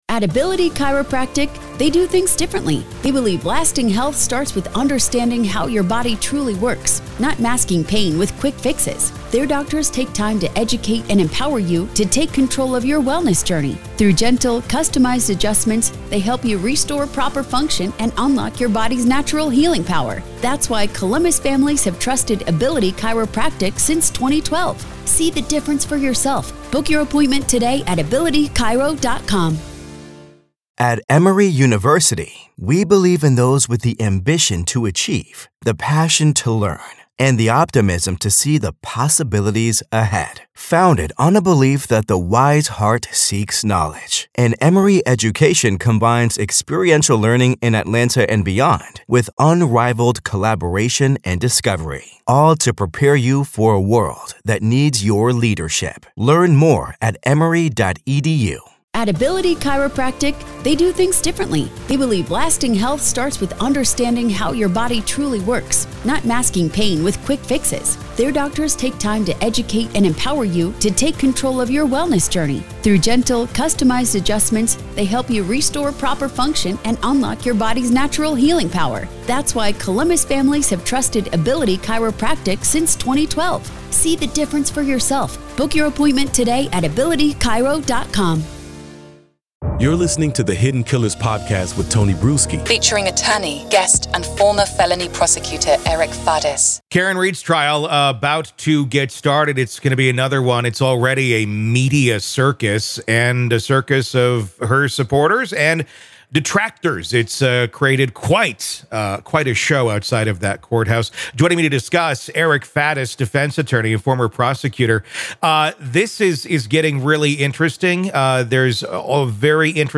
Main Points of the Conversation - Karen Read's defense is employing an alternative suspect strategy, recently approved for trial.